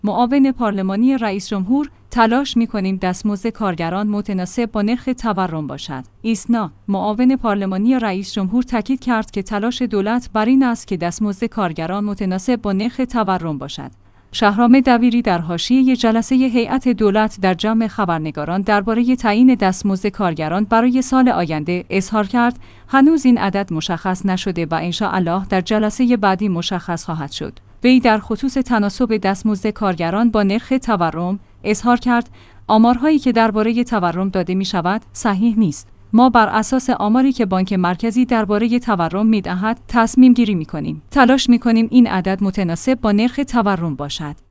ایسنا/معاون پارلمانی رئیس‌جمهور تاکید کرد که تلاش دولت بر این است که دستمزد کارگران متناسب با نرخ تورم باشد. شهرام دبیری در حاشیه جلسه هیات دولت در جمع خبرنگاران درباره تعیین دستمزد کارگران برای سال آینده، اظهار کرد: هنوز این عدد مشخص نشده و ان‌شاءالله در جلسه بعدی مشخص خواهد شد.